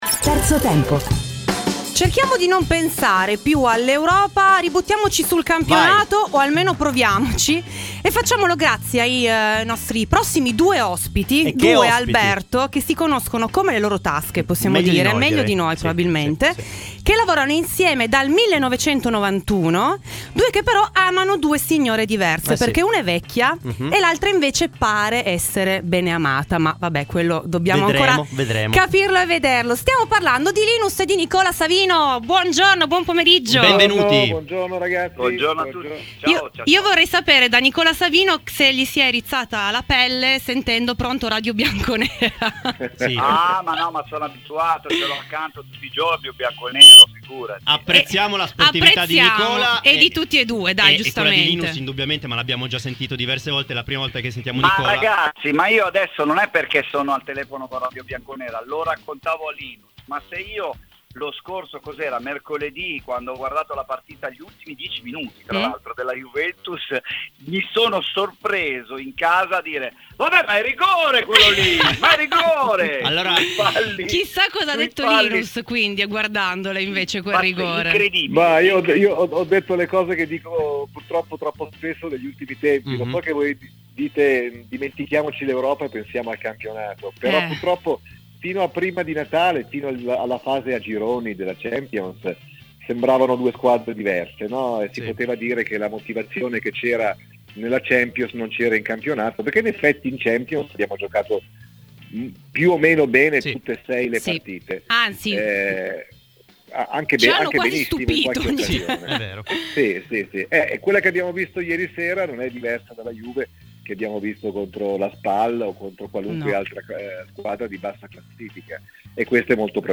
In vista di Juventus-Inter, Radio Bianconera ha intervistato i due conduttori radiofonici Linus e Nicola Savino, il primo juventino e il secondo interista. I due sono intervenuti nel corso di ‘Terzo Tempo’: